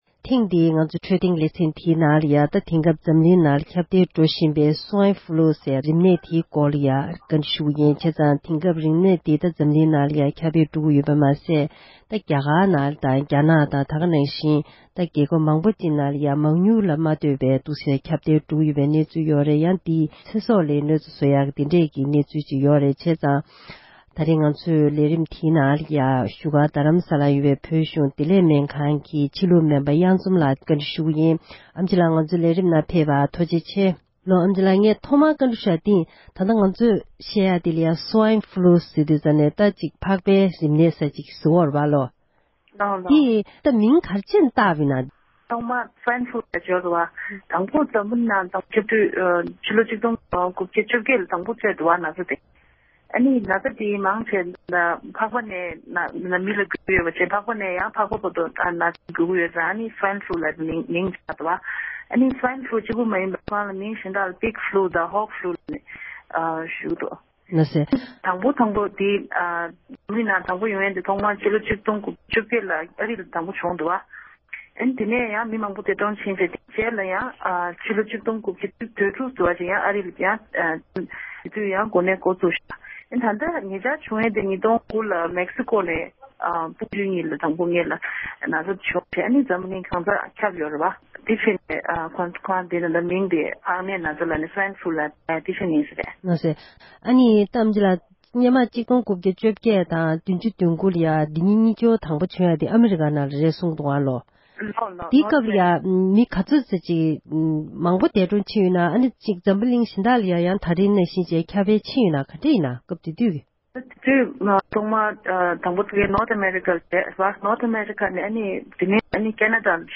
བཀའ་འདྲི་ཞུས་པར་གསན་རོགས༎